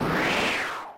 gust.mp3